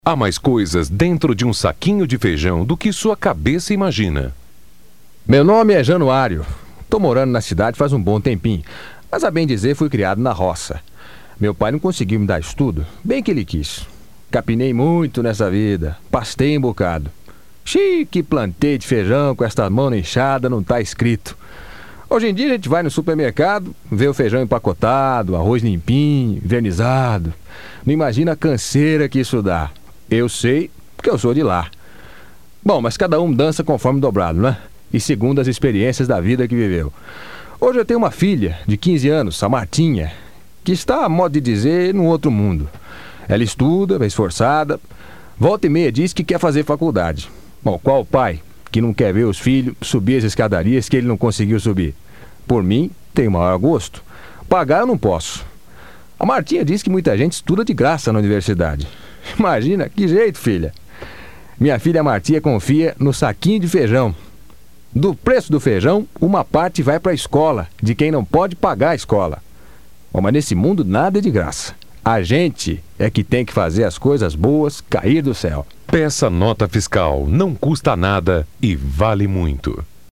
Por conta da relação entre arrecadação de ICMS embutido nas coisas/serviços comprados pela população e o aumento de recursos para as Universidades Públicas Estaduais (USP, UNICAMP e UNESP),  a OBORÉ preparou um pacote de 5 vinhetas de rádio para colaborar em uma campanha contra a sonegação fiscal no estado.